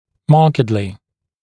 [‘mɑːkɪdlɪ][‘ма:кидли]заметно, явно